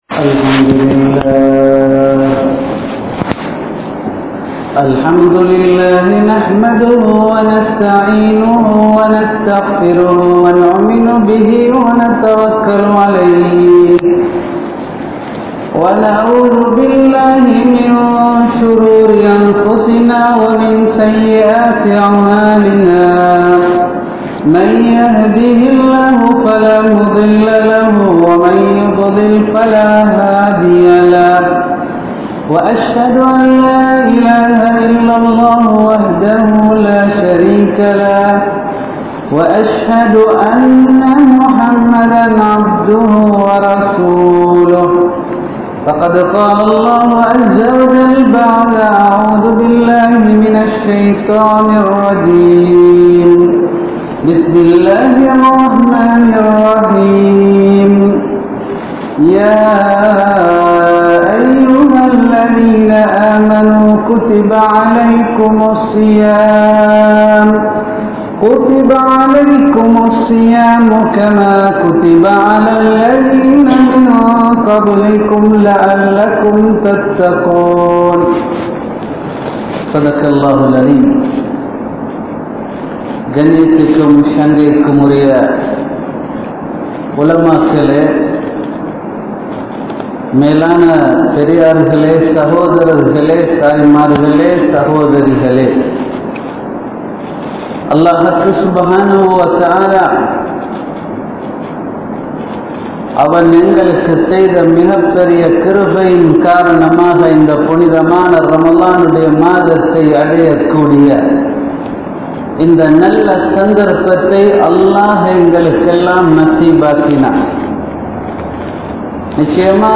Porumaien Kooli (பொறுமையின் கூலி) | Audio Bayans | All Ceylon Muslim Youth Community | Addalaichenai
Wellampitiya, Polwatte, Masjidun Noor Jumua Masjidh